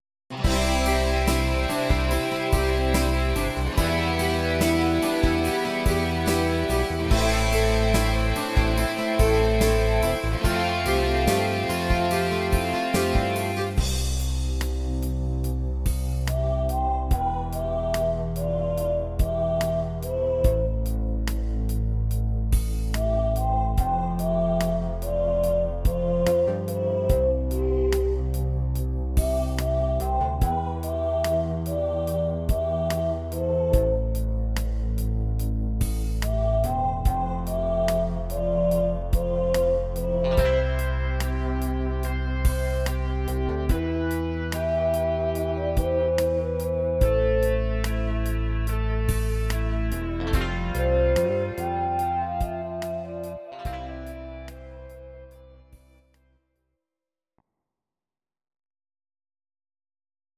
Audio Recordings based on Midi-files
Rock, 1990s